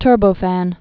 (tûrbō-făn)